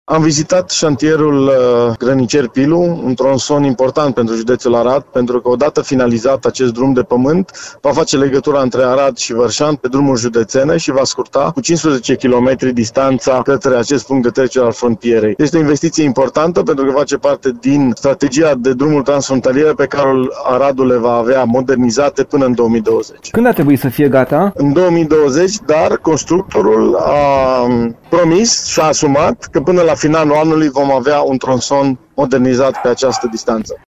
Vicepreşedintele Consiliului Judeţean, Sergiu Bâlcea, a inspectat şantierul şi a precizat că investiţia ar putea fi gata înainte de termen.